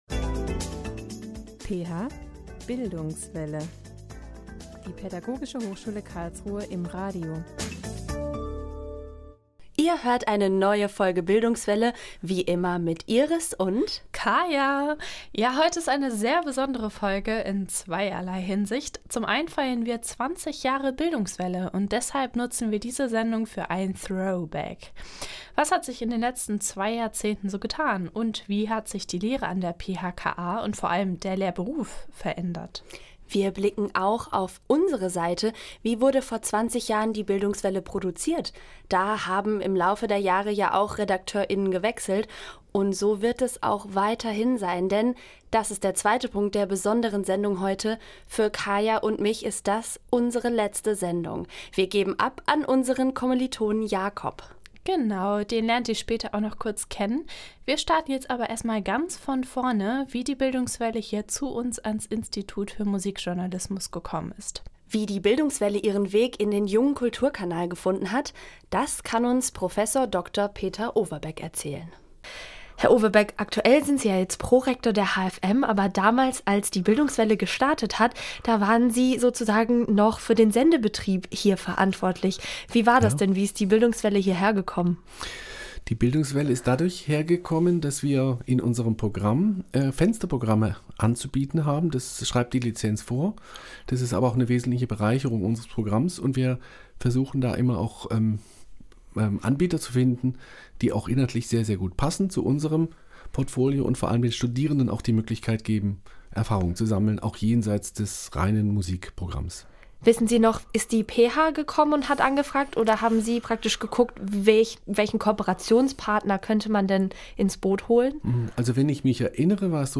Was hat sich in den letzten zwei Jahrzehnten so verändert? Wir blicken auf die Lehre an der PHKA, sprechen mit aktuellen und ehemaligen Studierenden und erklären euch, wie die Bildungswelle überhaupt entstanden ist.